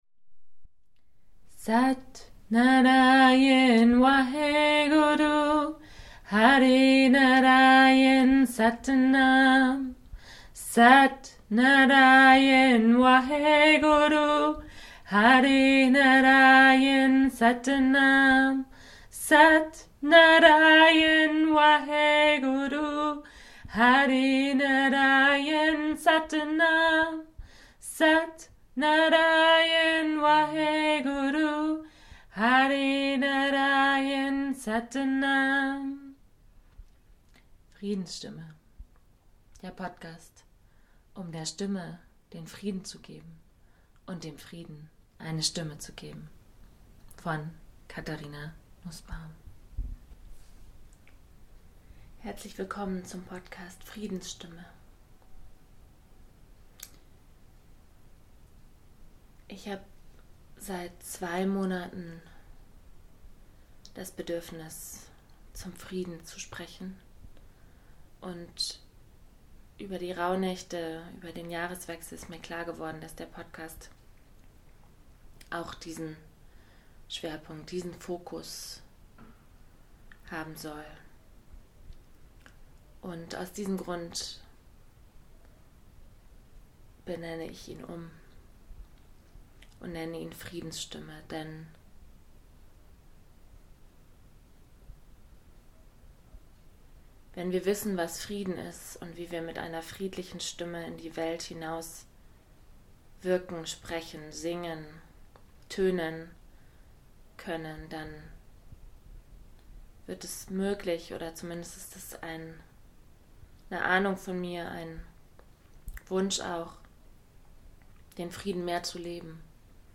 Zwischendurch hörst du den UHU von unserer Wanduhr tönen.